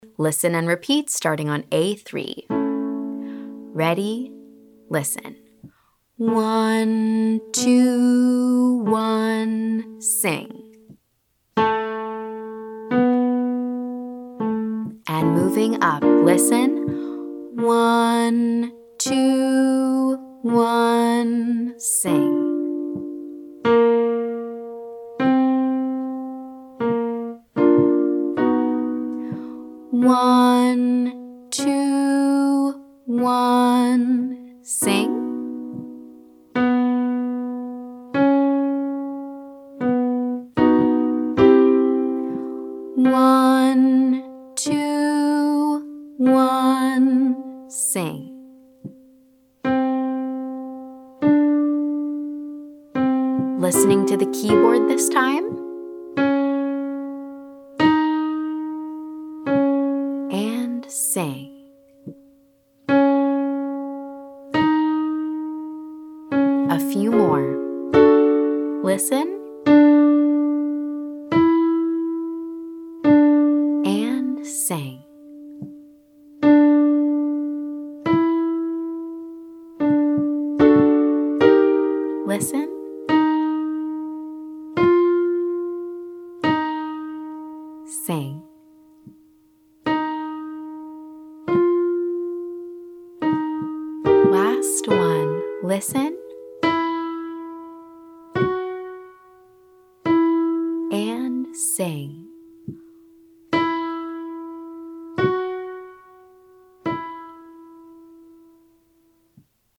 Exercise - whole step, listen & repeat